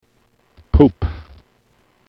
Poop